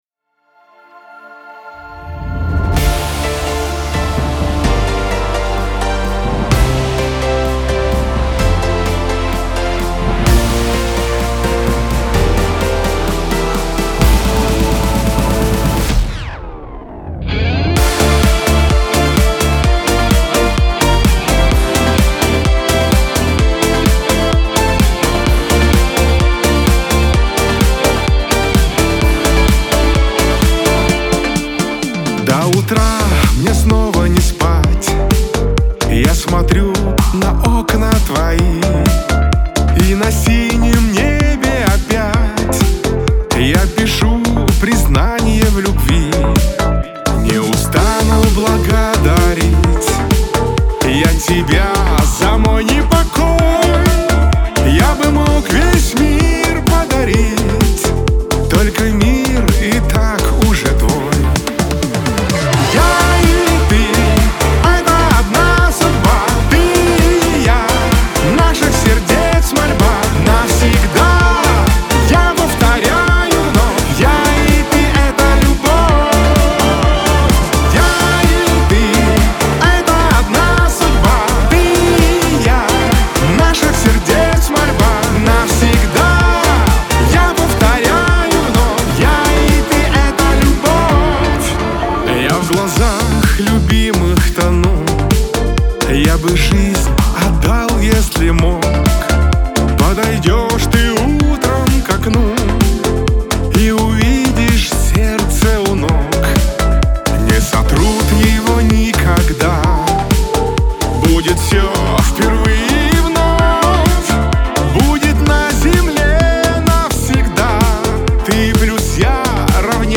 pop , эстрада